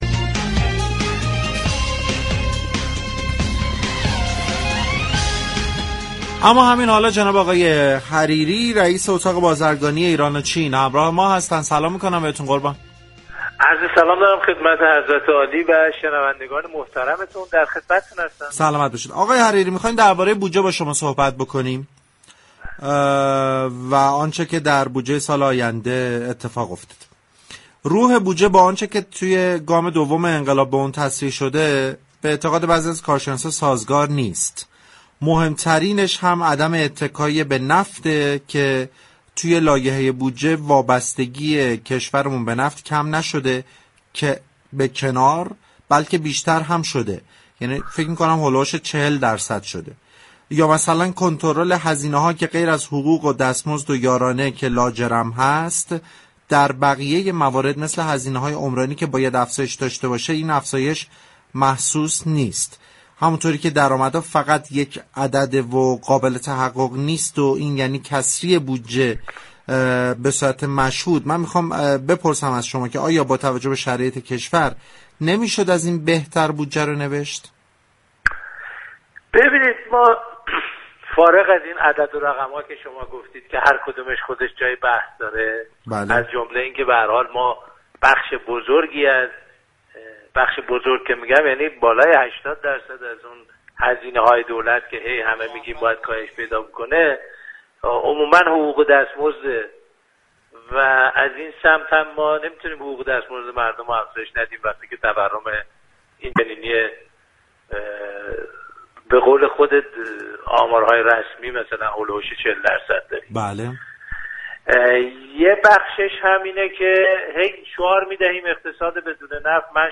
در گفتگو با برنامه